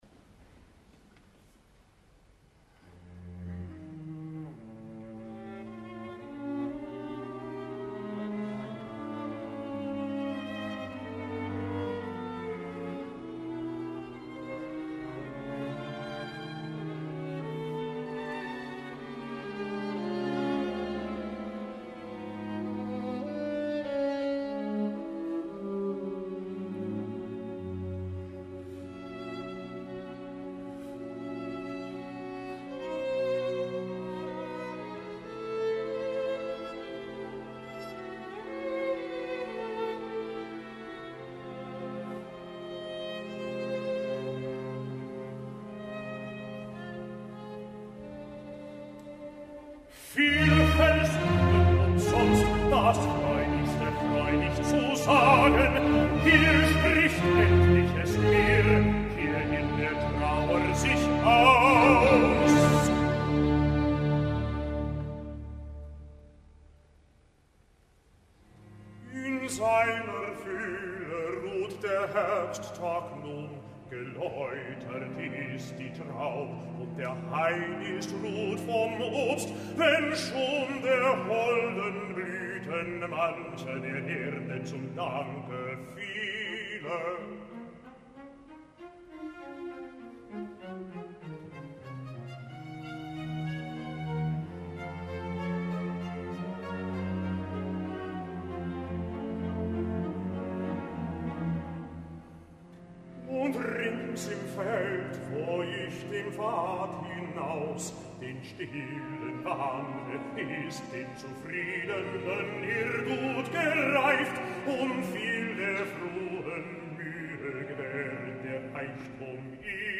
avui és Thomas Hampson cantant Ernste Gesänge
dirigit per Christian Thielemann en un concert celebrat dins el Festival de Lucerna